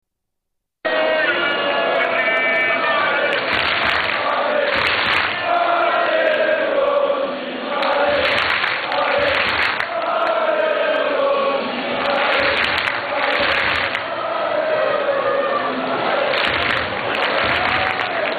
Chant de supporters